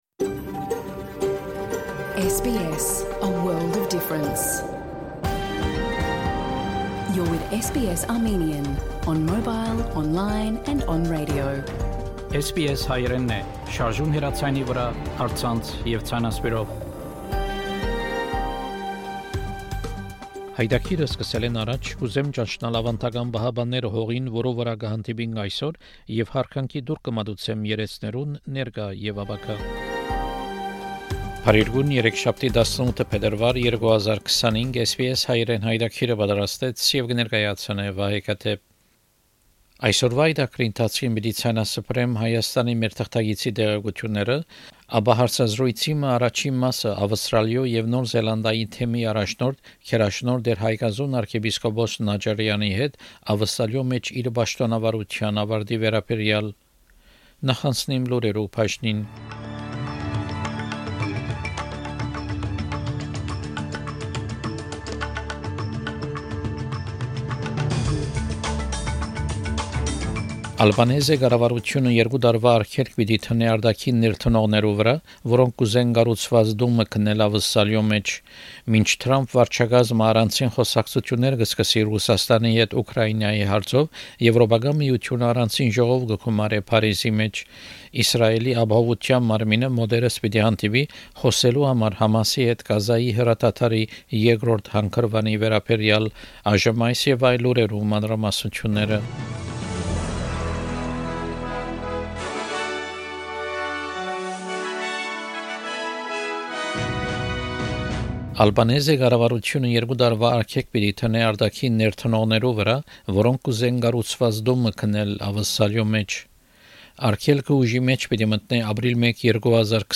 SBS Armenian news bulletin from 18 February 2025 program.